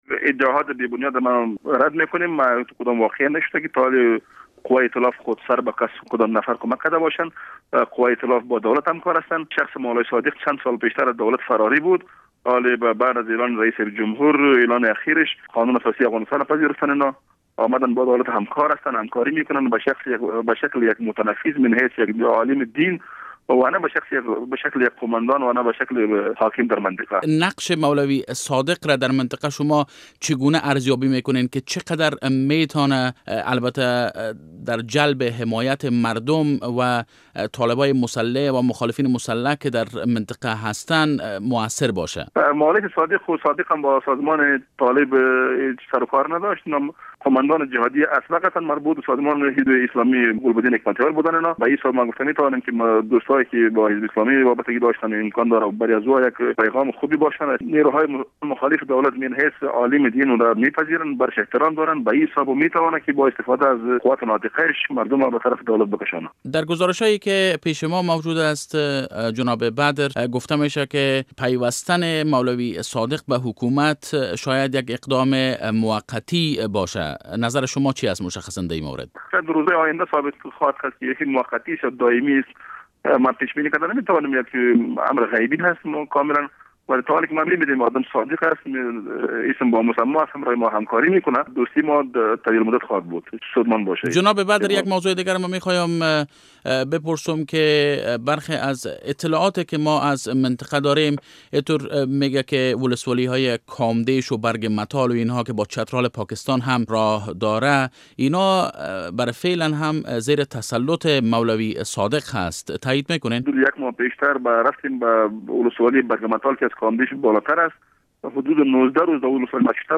مصاحبه با والی نورستان در مورد کمک نیروهای امریکایی به یک قوماندان سابق جهادی